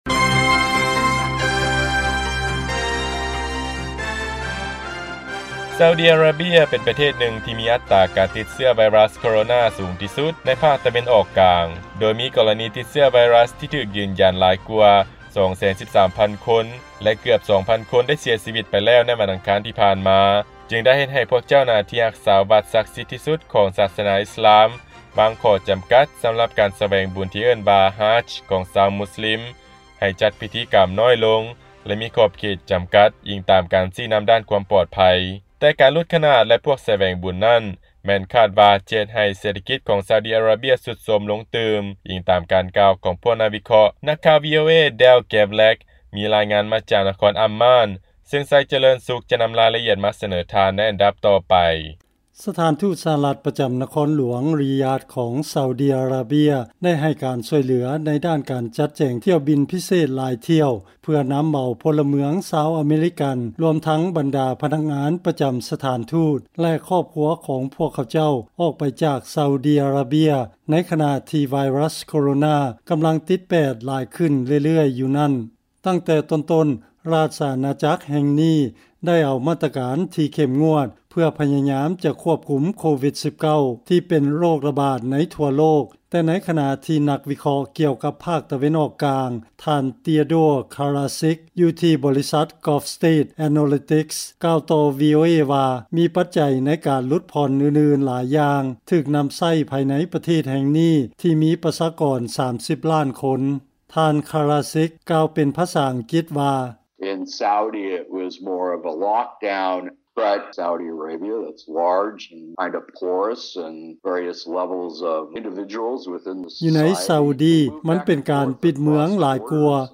ເຊີນຟັງລາຍງານ ໃນຂະນະ ກໍລະນີຕິດເຊື້ອໄວຣັສໂຄວິດ ເພີ່ມສູງຂຶ້ນ ຊາອຸດີອາຣາເບຍ ຈຶ່ງໄດ້ວາງຂໍ້ຈຳກັດຕ່າງໆ ໃນພິທີສະແຫວງບຸນຮາດຈ໌